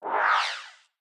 Space Swoosh - brighter